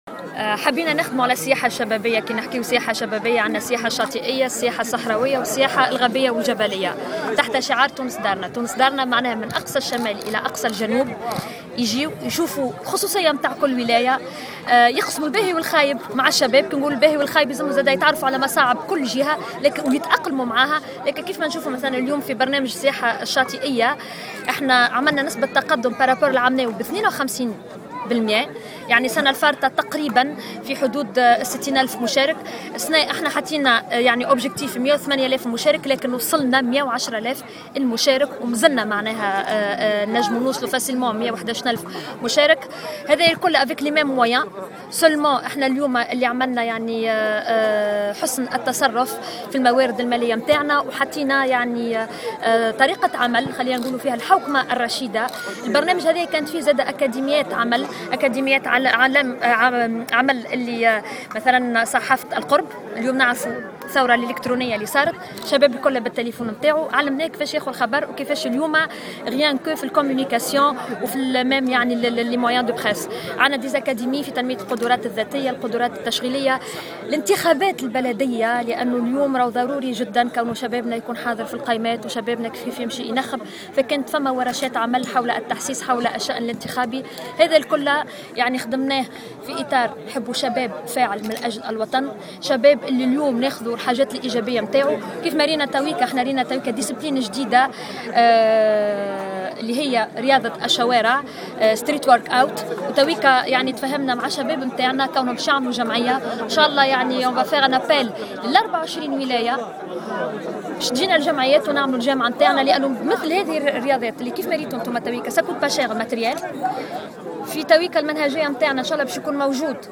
وقالت في تصريح لمراسل "الجوهرة أف أم" على هامش أشغال الندوة الدورية للمندوبين الجهويين لشؤون الشباب والرياضة بالمنستير، إنه سيتم توجيه نداء لكل الولايات الـ 24 لتشجيع مثل هذا النشاط الشبابي من خلال اطلاق جمعيات قبل تحولها الى جامعة ومما سيساهم في التصدي للادمان وحتى الارهاب.